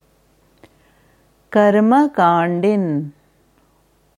Dieses Wort wird im Devanagari Skript geschrieben कर्मकाण्डिन्, in der wissenschaftlichen IAST Transliteration karma-kāṇḍin. Hier hörst du, wie man Karma Kandin ausspricht.